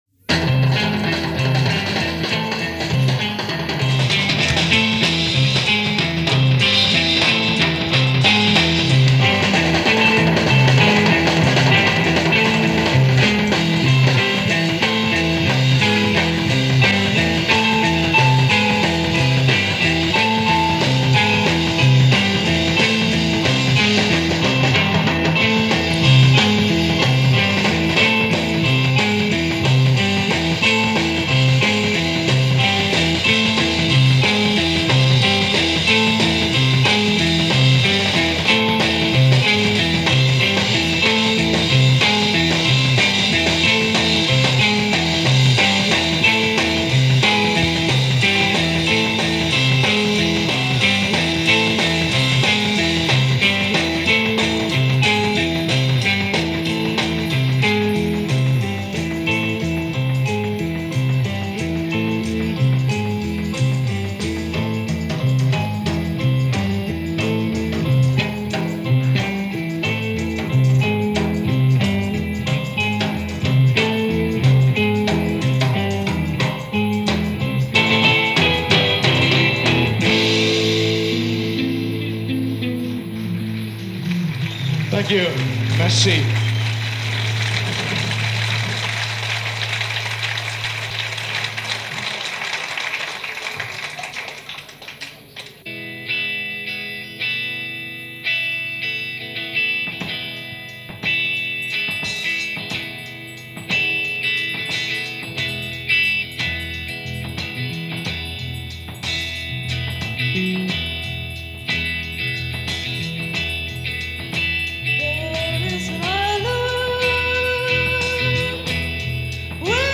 keyboards
bass
experimental with touches of Folk and Jazz